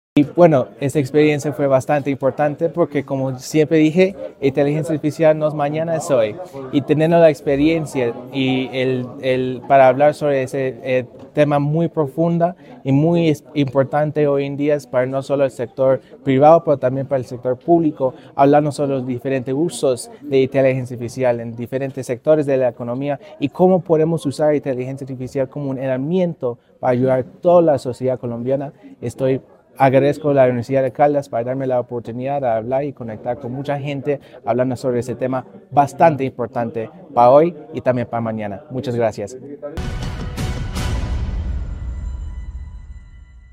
La Secretaría de Planeación de Caldas, en articulación con la Universidad de Caldas y el proyecto Talento Tech, lideró el Foro Departamental “Gobiernos Caldenses en la Revolución Digital”, un espacio orientado a analizar los retos y oportunidades que ofrece la inteligencia artificial (IA) para el desarrollo territorial.